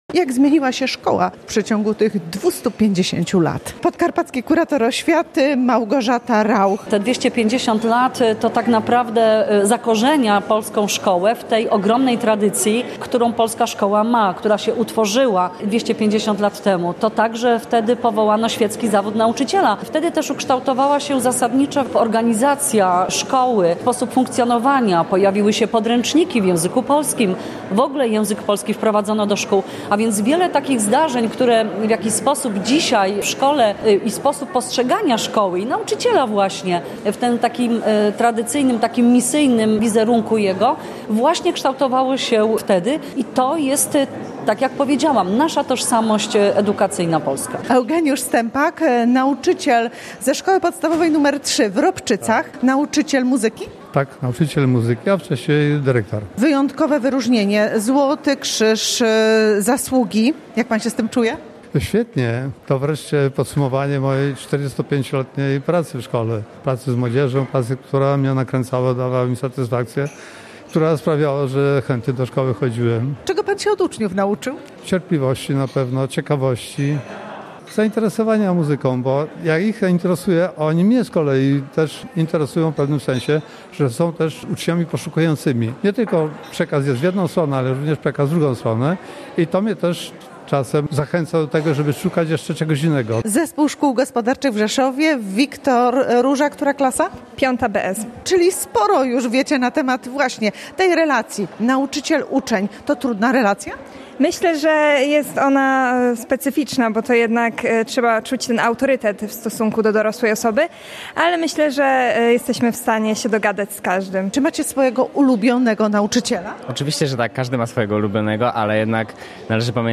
Podczas wojewódzkich uroczystości, które odbyły się w Filharmonii Podkarpackiej im. Artura Malawskiego w Rzeszowie, nauczycielom i pracownikom oświaty zostały wręczone Krzyże Zasługi, Medale za Długoletnią Służbę, Medale Komisji Edukacji Narodowej, nagrody Ministra Edukacji i Nauki oraz nagrody Podkarpackiego Kuratora Oświaty.